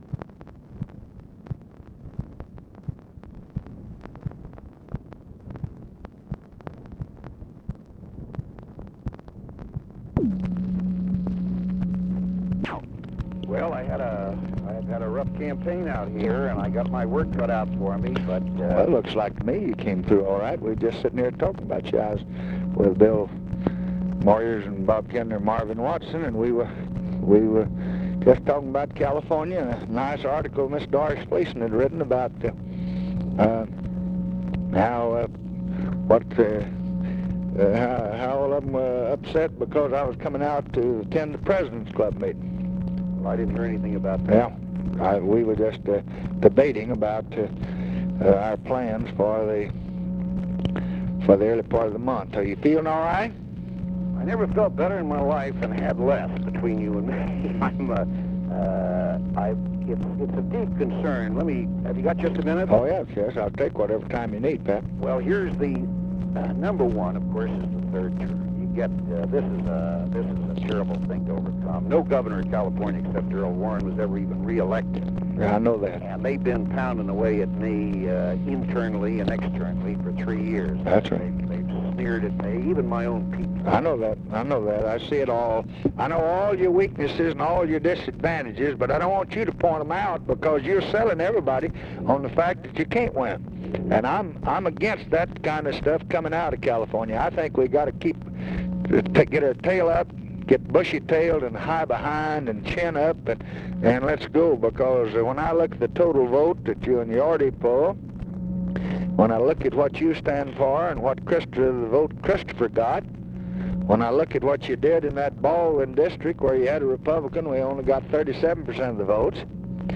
Conversation with EDMUND G. BROWN and OFFICE CONVERSATION, June 13, 1966
Secret White House Tapes